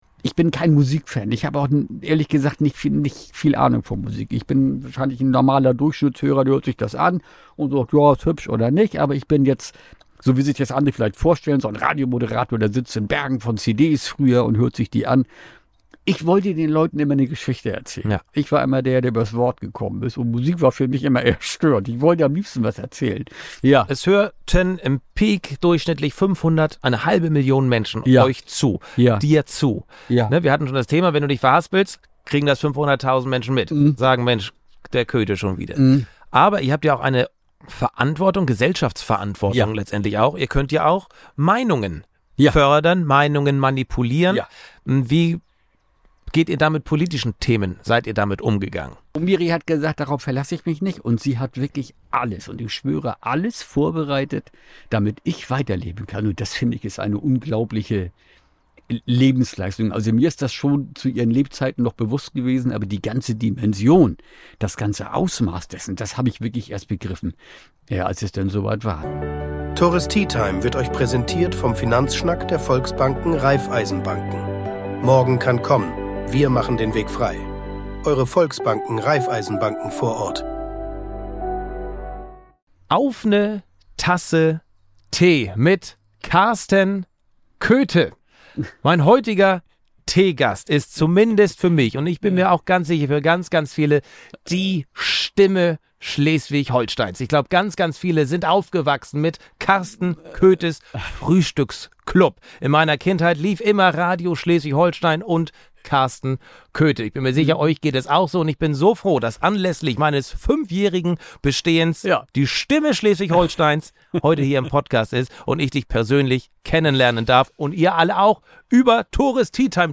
In meinem mobilen Podcaststudio sprachen wir